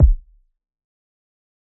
{Kick} Maverik.wav